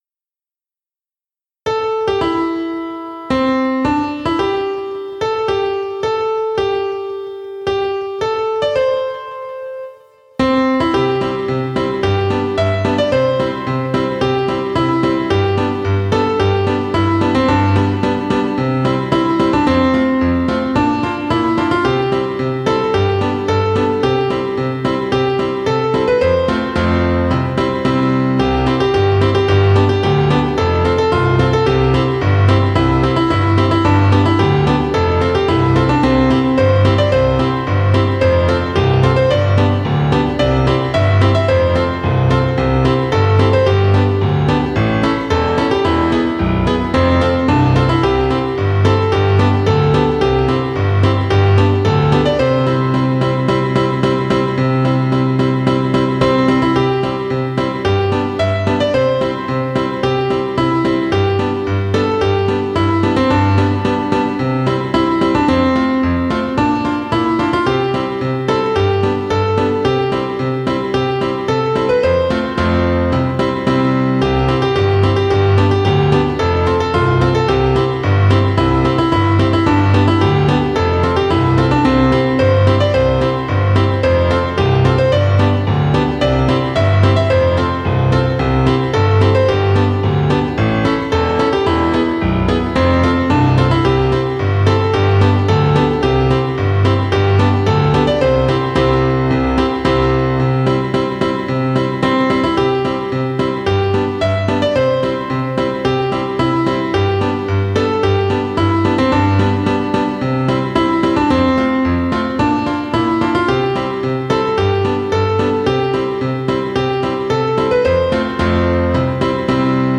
校歌/伴奏    1954年（昭和29年） - 熊本県立商業高等学校別科今津分室創設
kumasho_matusima_kouka_bansou.mp3